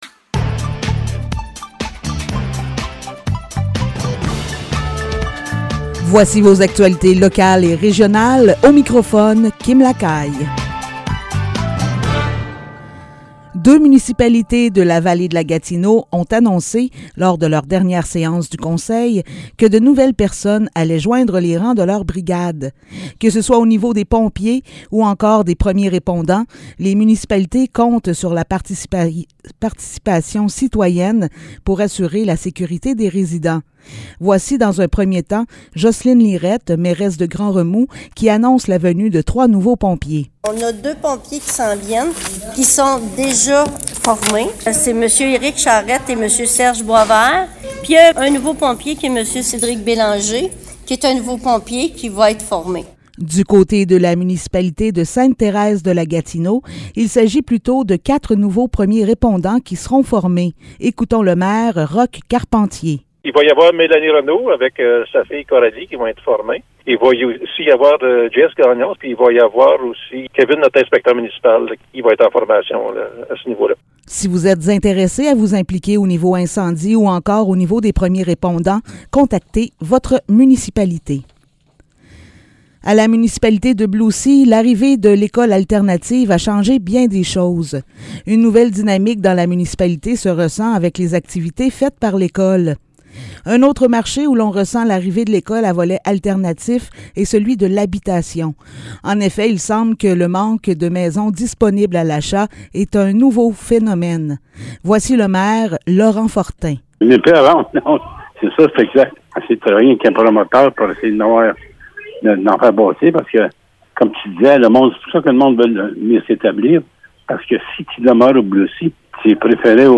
Nouvelles locales - 30 novembre 2021 - 15 h